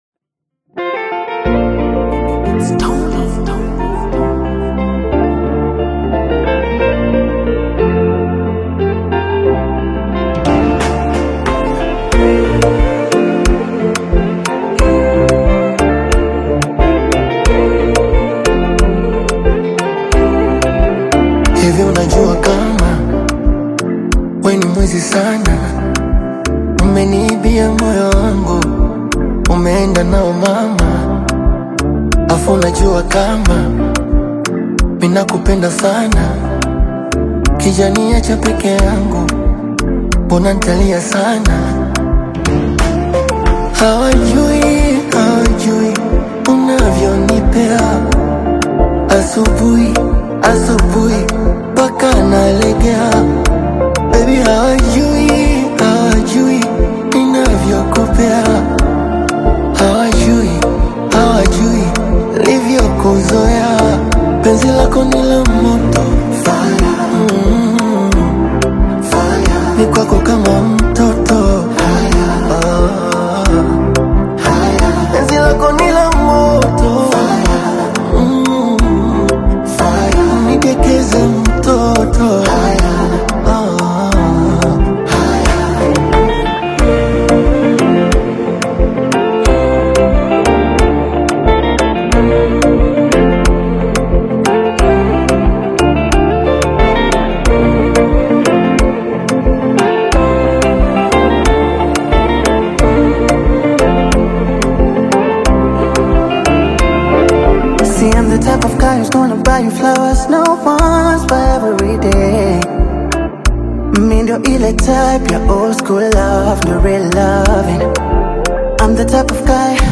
fiery Afrobeats/Afro‑Fusion duet
fusing catchy hooks with vibrant melodic energy